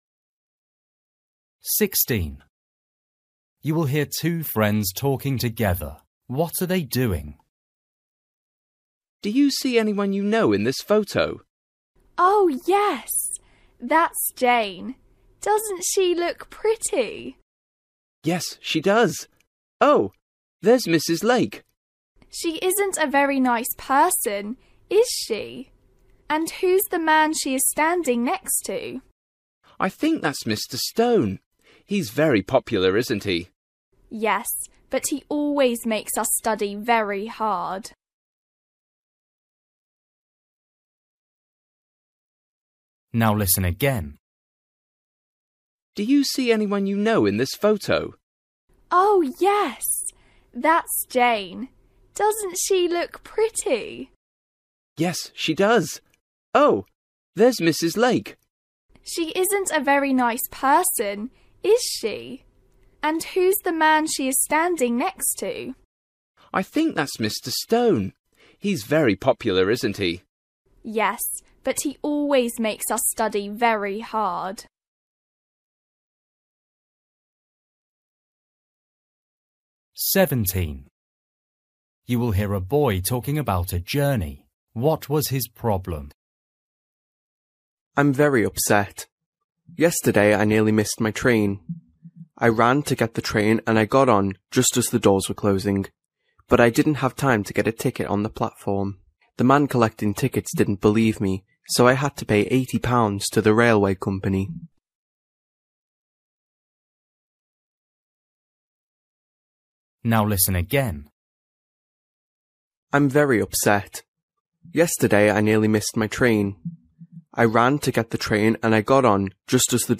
Listening: everyday short conversations
các cuộc hội thoại ngắn hàng ngày
16   You will hear two friends talking together. What are they doing?
17   You will hear a boy talking about a journey. What was his problem?